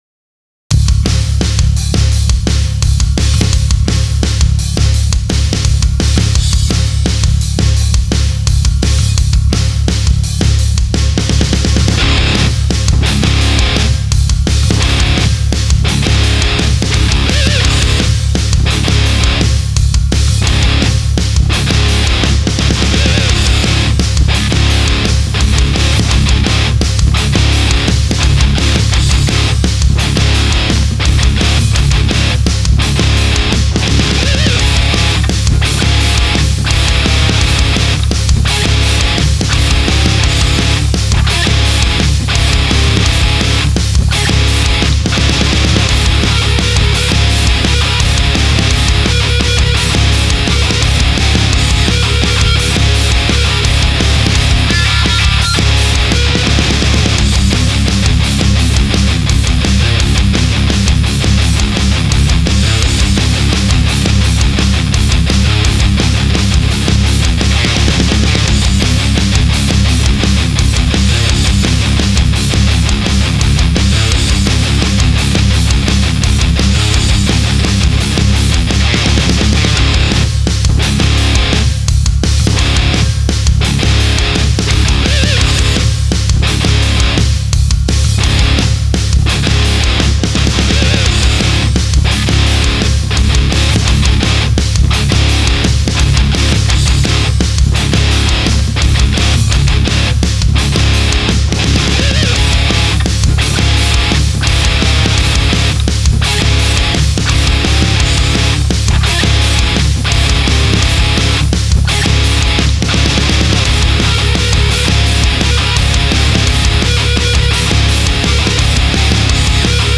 キレのある鋭いリフからひきずるような粘っこいリフが印象的